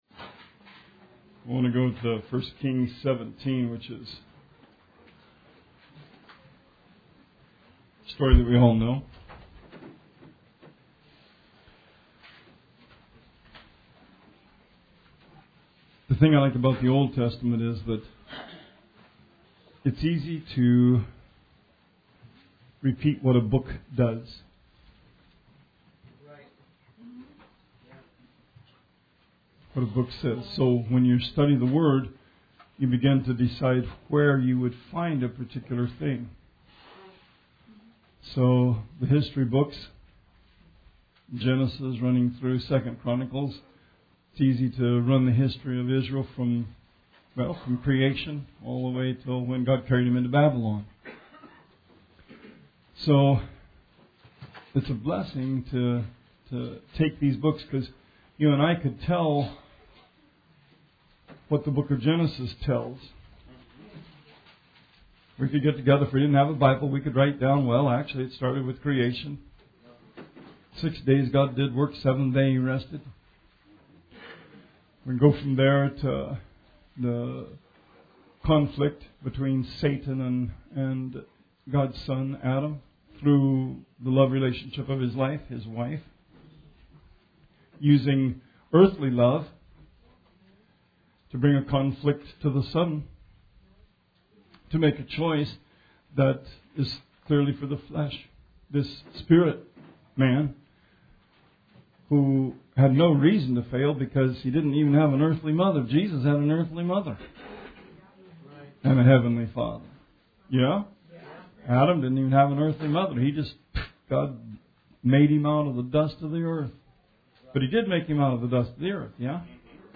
Sermon 12/31/17 AM